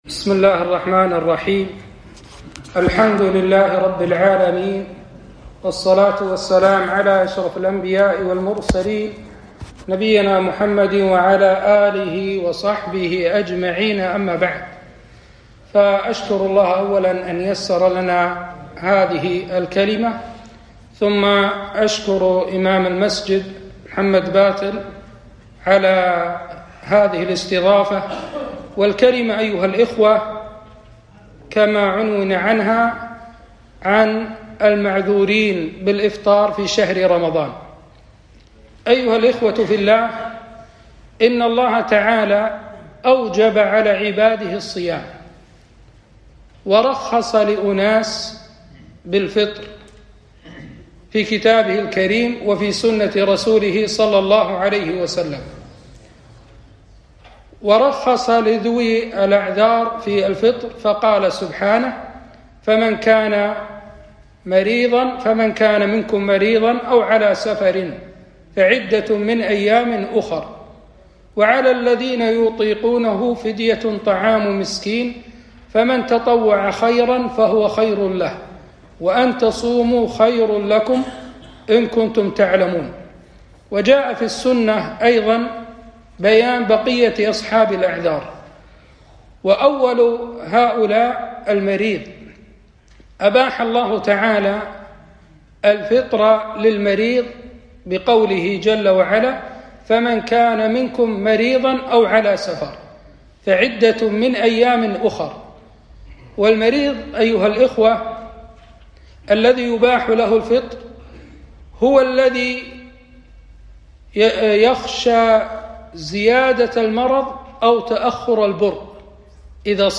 كلمة - المعذورون بالإفطار في شهر رمضان